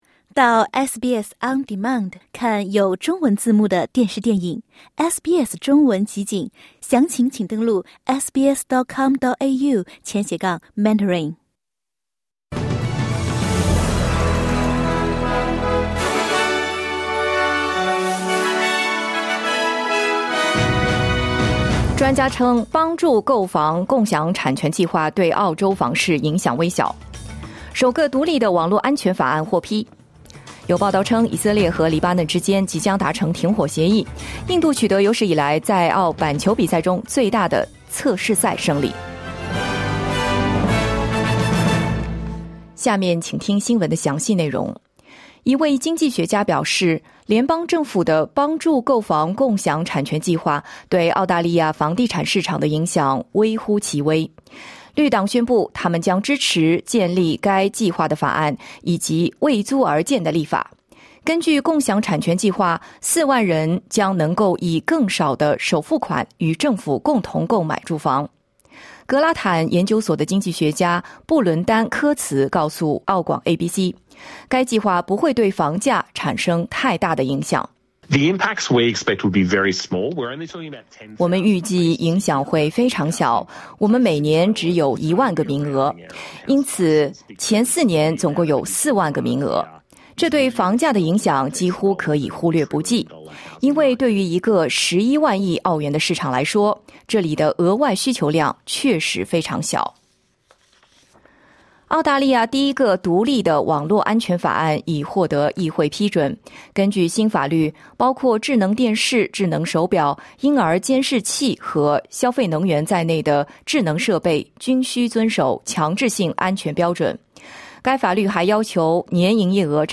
SBS早新闻（2024年11月26日）